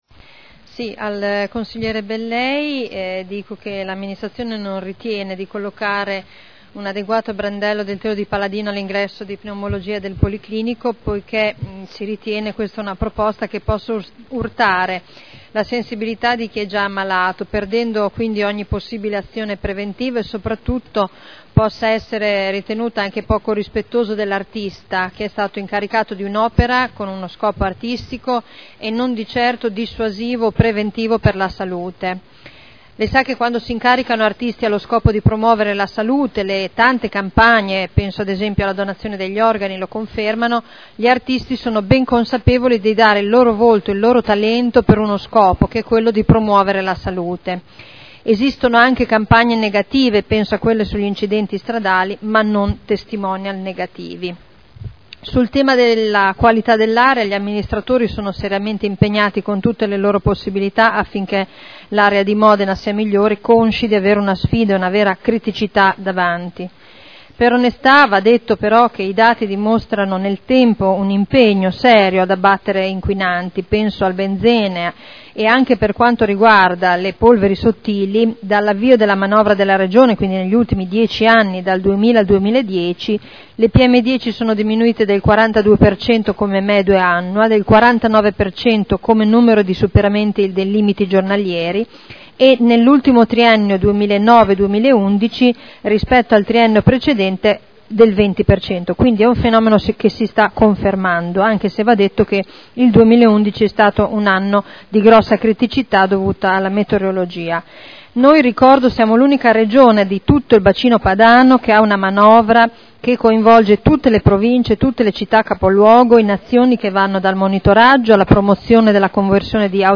Seduta del 27 febbraio. Risposta dell'assessore Arletti sulle interrogazioni dei consiglieri Bellei e Ballestrazzi sul telo di Paladino